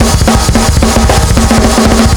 Index of /m8-backup/M8/Samples/musicradar-metal-drum-samples/drums acoustic/220bpm_drums_acoustic